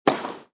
Tennis Serve.m4a